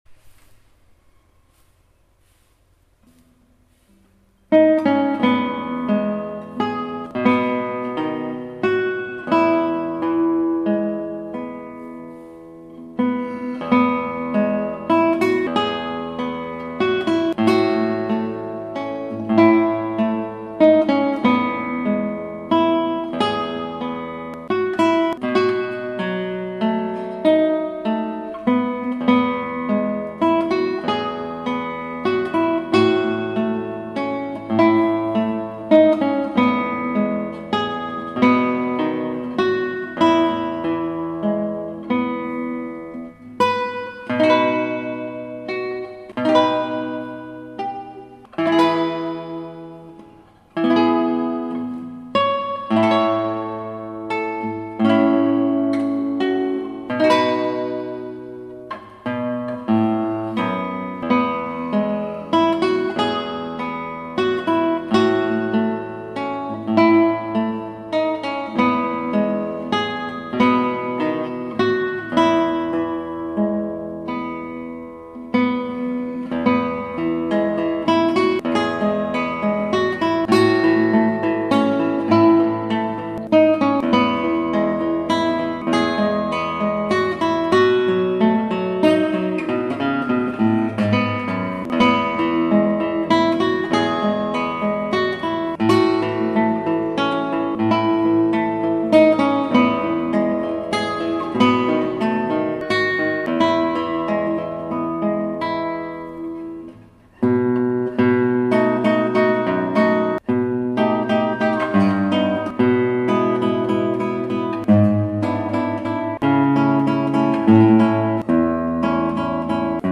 浜辺の歌、マリンで、